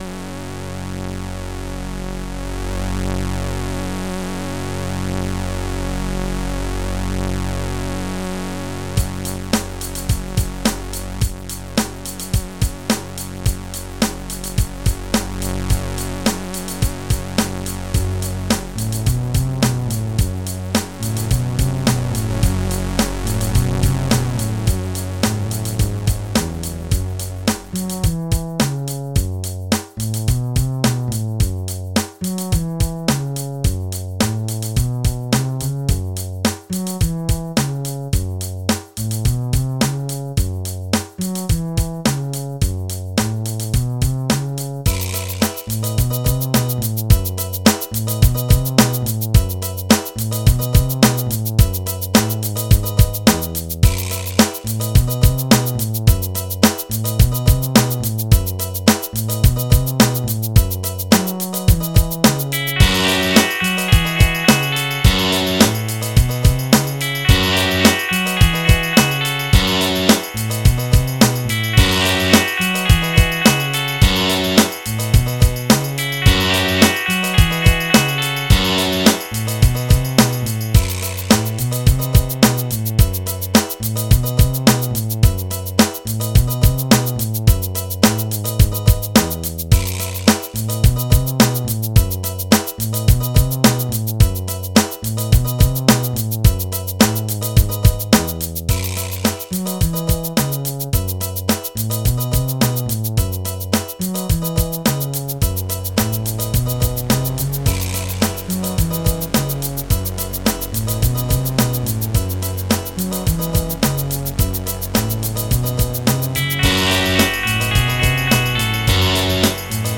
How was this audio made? being played through the FluidR3 GM. It's night and day.